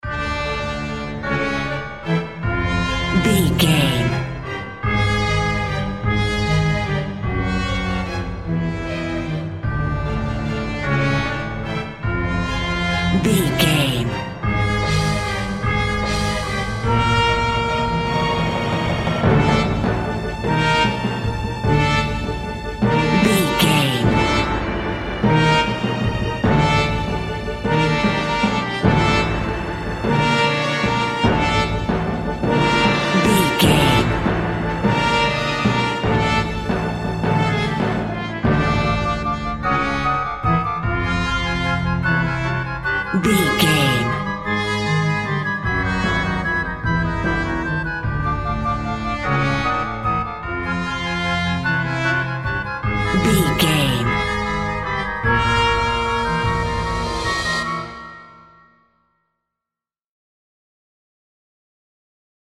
Diminished
D
scary
tension
ominous
dark
suspense
dramatic
haunting
eerie
epic
powerful
brass
percussion
flute
Horror synth
Horror Ambience
synthesizer